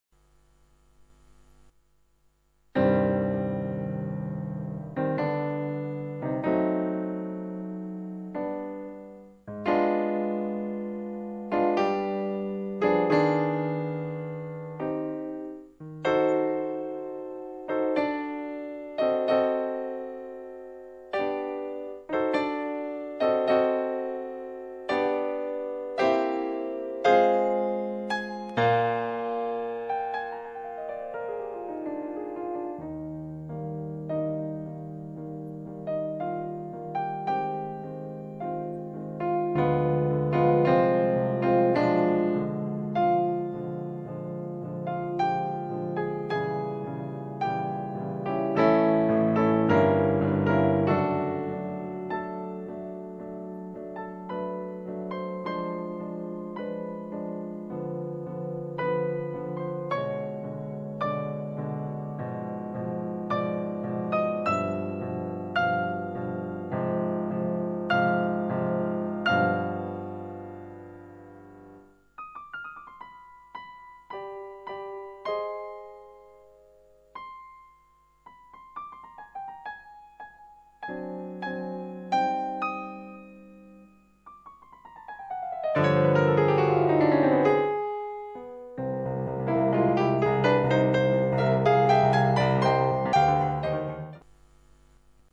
Piccola demo del piano chiamato "Bright Concert Piano"
In sostanza c'è solo un graduale taglio di frequenze basse decisamente più leggero (in realtà via via che la frequenza scende, aumenta il taglio, ma sempre moderatamente), e una piccola enfatizzazione degli acuti.
non male ma nelle basse dinamiche non è realistico, sicuramente come multisample a pochi livelli di layer e dunque gioca con il filtro...e si sente
European-Piano-_perf-098_---Beethoven.mp3